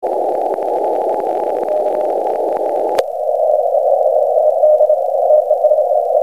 パリパリという音がなくて、要するに聞きやすいんです。
ですよね(^^;;　前半がR-4C、後半が950です。この時はQSBもありましたからまともなサンプルにはなってませんが、これ以降は近くで強い信号がない限り、弱くて聞きづらい時はR-4Cにして聞く事もしばしばでした。